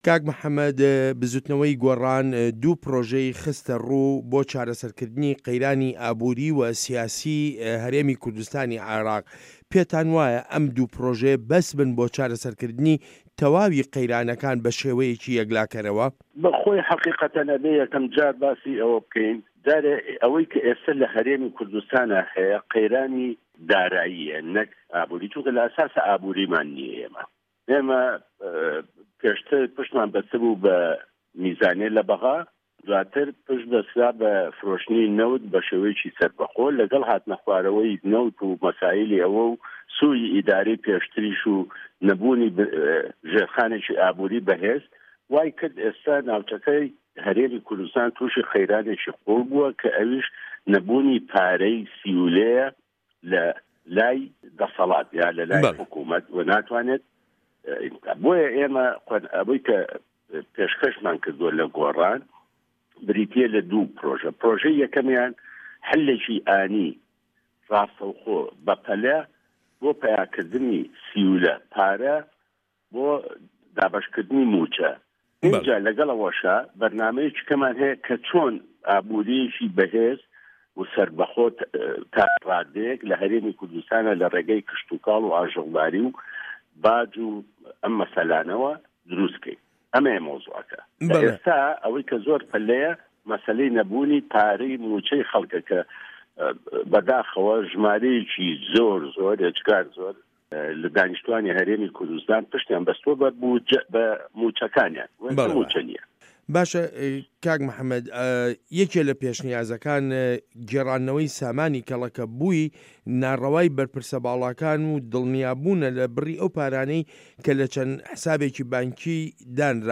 وتووێژ لەگەڵ موحەمەد تۆفیق ڕەحیم